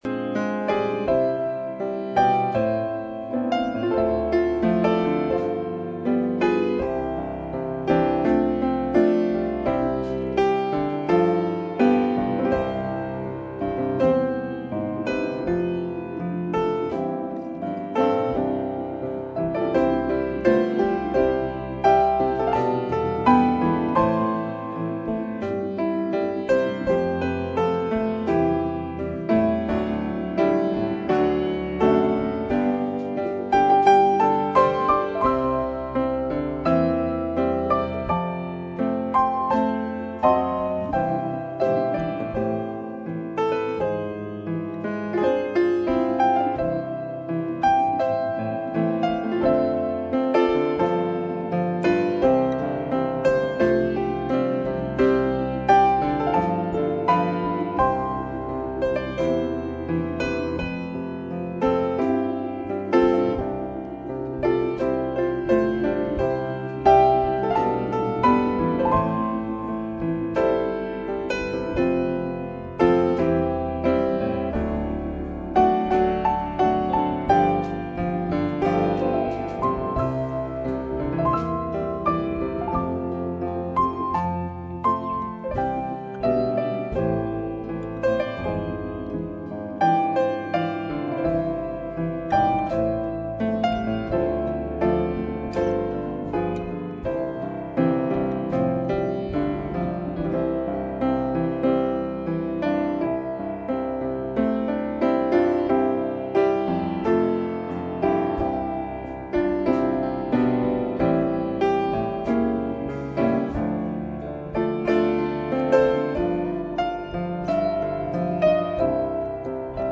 圣餐聚会（你为我预备筵席）